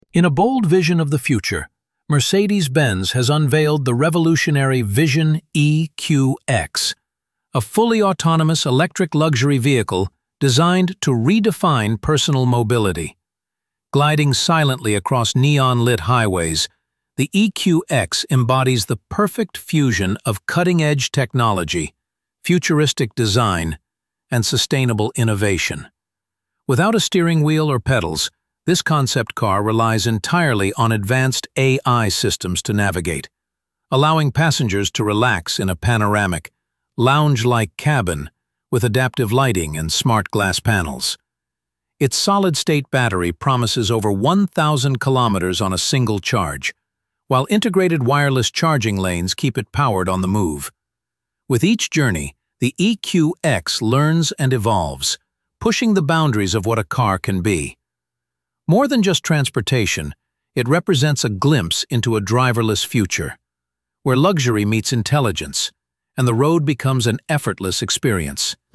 AI narration • Black background • Electric blue text • Glowing blue shadow